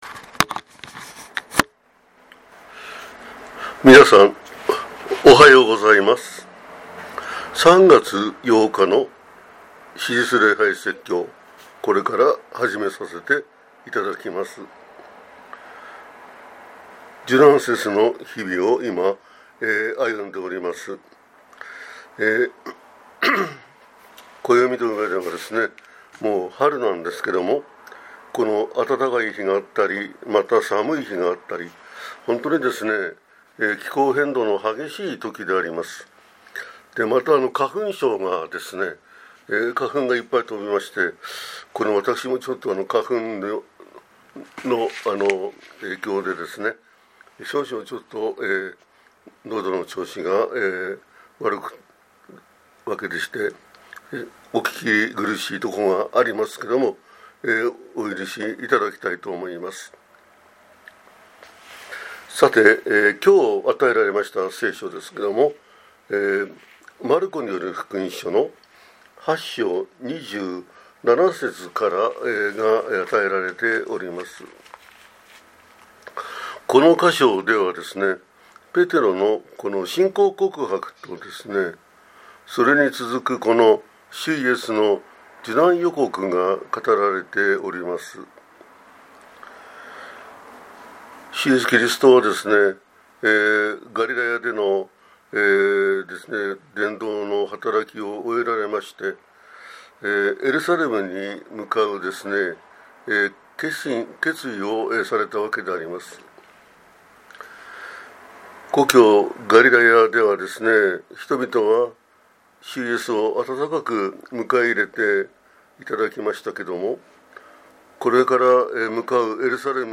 2026年3月8日（受難節第3主日） - 日本基督教団 川口教会